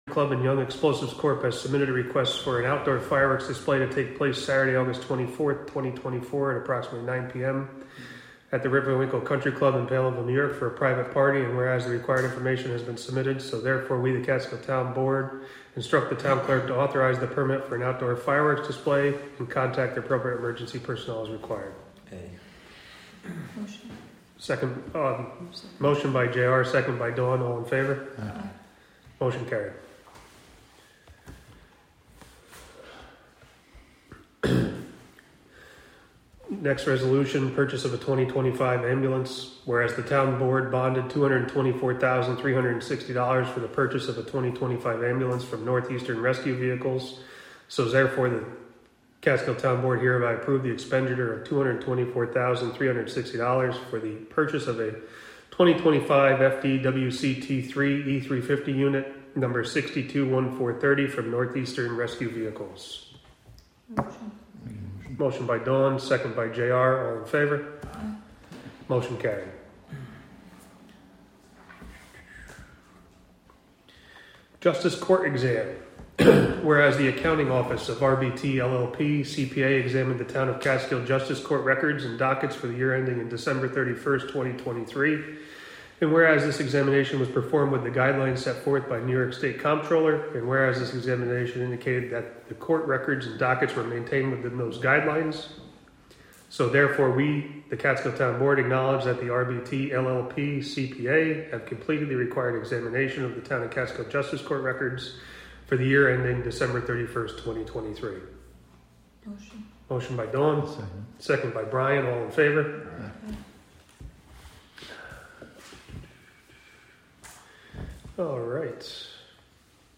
Live from the Town of Catskill: August 21, 2024 Catskill Town Board Meeting (Audio)